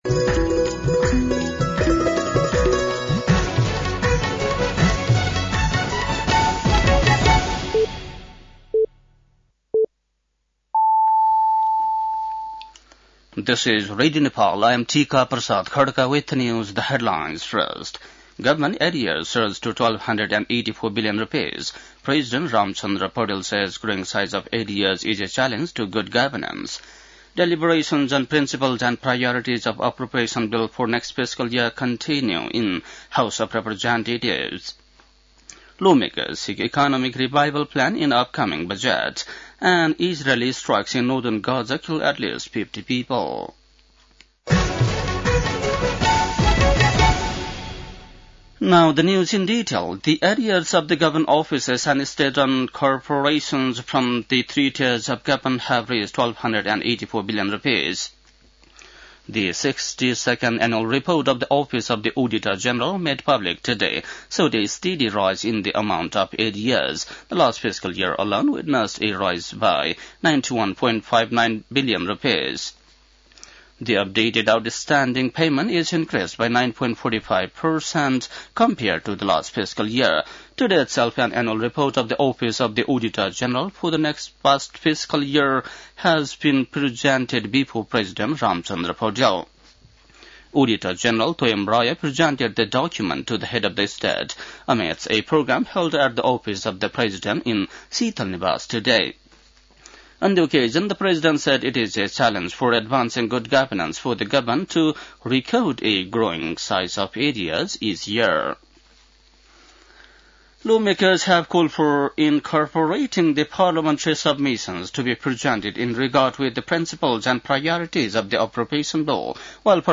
An online outlet of Nepal's national radio broadcaster
बेलुकी ८ बजेको अङ्ग्रेजी समाचार : ३१ वैशाख , २०८२